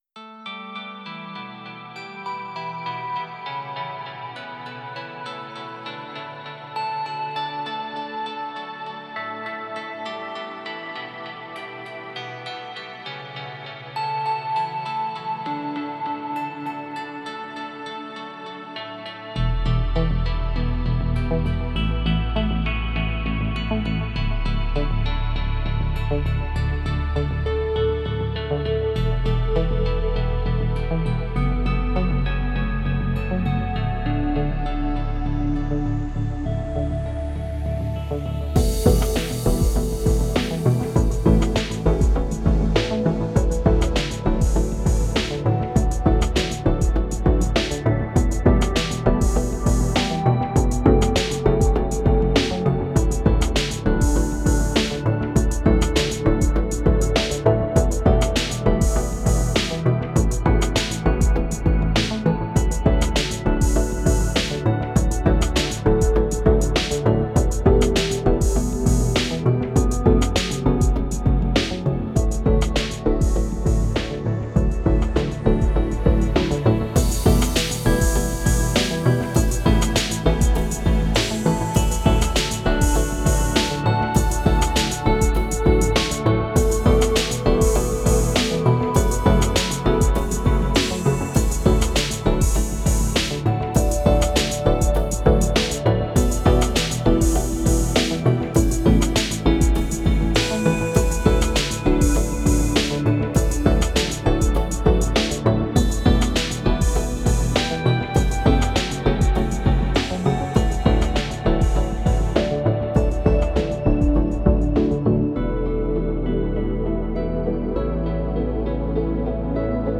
dj_vibes_edm_by_the_sea_house_music.mp3